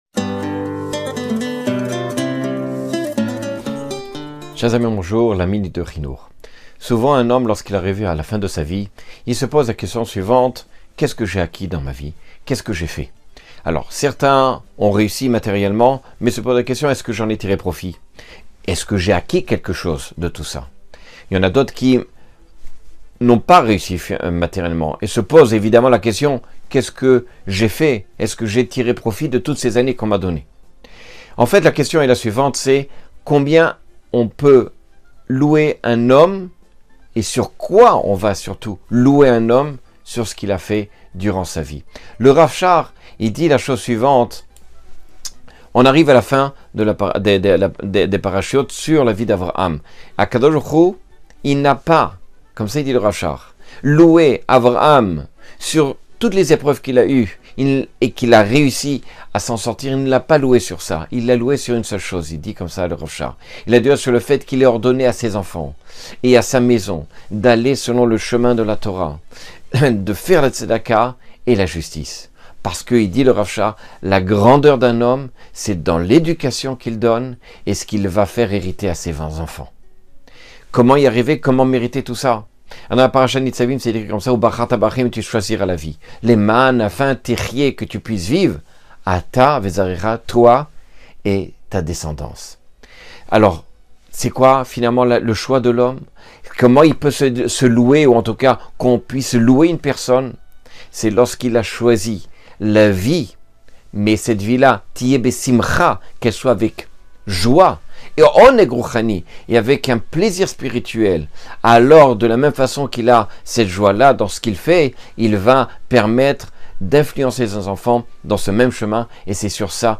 Cours de 1 minute et des poussières sur l’éducation (le ‘hinoukh).